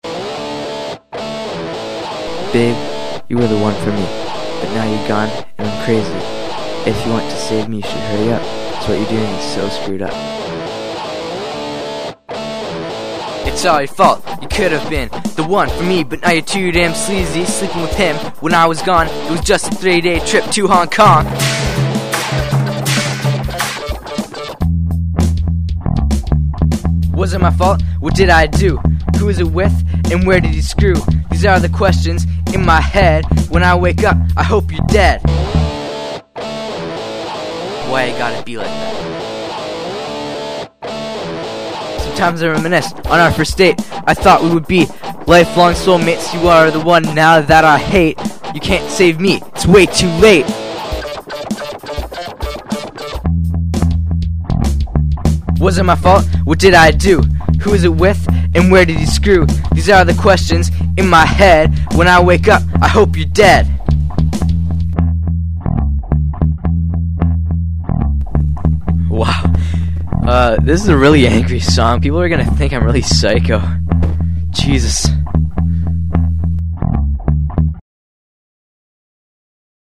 Drums
Guitar
Vocals
Keyboard
Bass/German Rapper